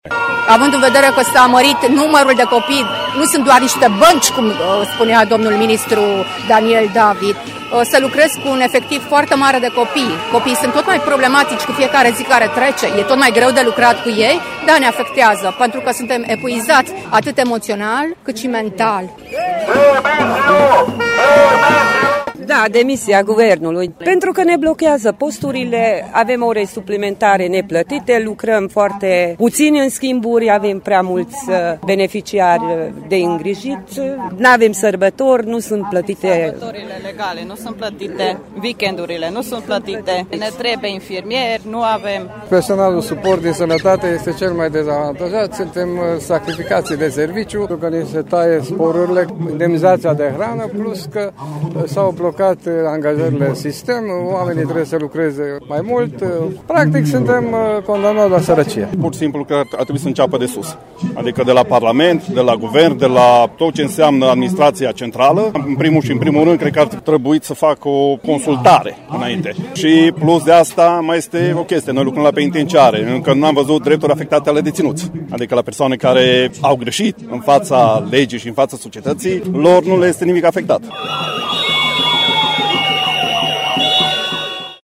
Câteva sute de lucrători din cadrul sindicatelor, federațiilor și confederațiilor sindicale din județul Mureș au pichetat astăzi Instituția Prefectului Mureș, nemulțumiți de măsurile de austeritate care afectează toate categoriile sociale, mai puțin cele privilegiate.